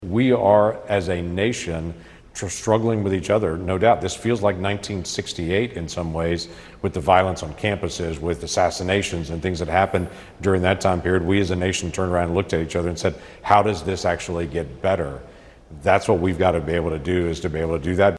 Oklahoma's senior senator, James Lankford, appeared on CNN's "The Arena" Thursday night, with thoughts about the nation after the assassination of Charlie Kirk.